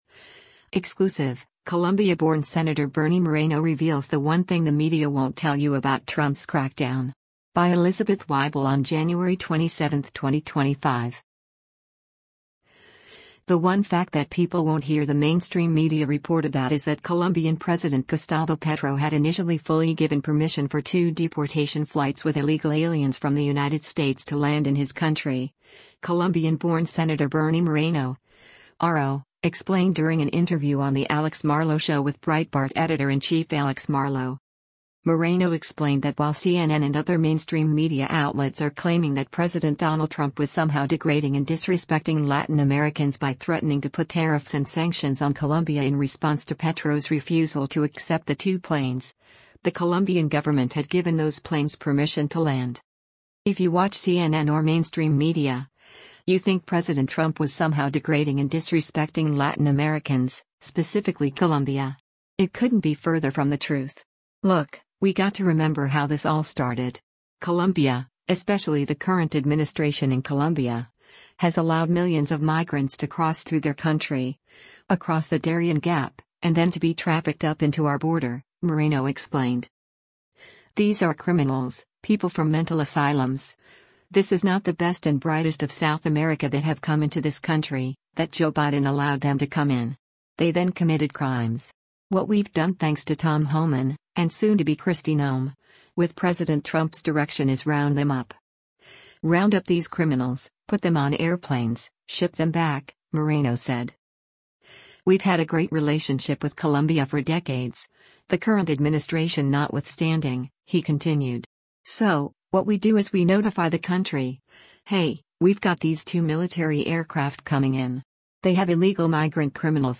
The “one fact” that people won’t hear the mainstream media report about is that Colombian President Gustavo Petro had initially “fully given permission” for two deportation flights with illegal aliens from the United States to land in his country, Colombian-born Sen. Bernie Moreno (R-OH) explained during an interview on The Alex Marlow Show with Breitbart Editor-in-Chief Alex Marlow.